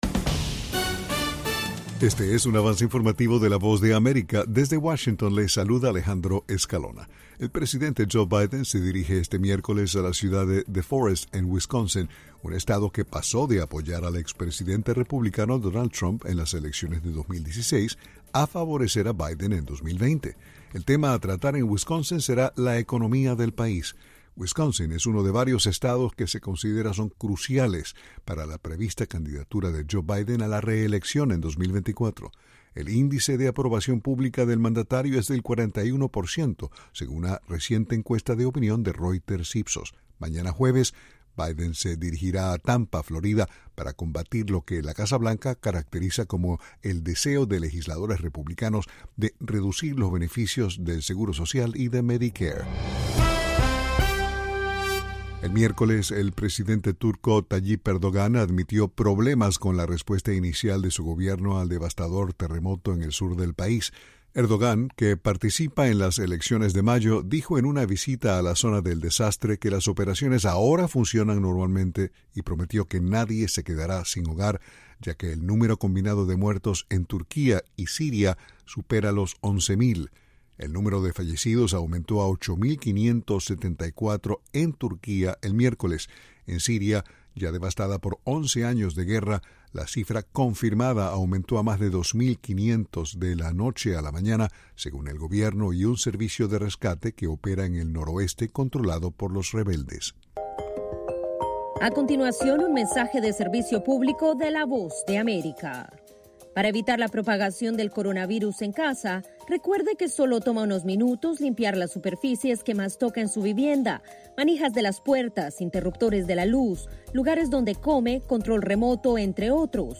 Avance Informativo
Este es un avance informativo presentado por la Voz de América en Washington.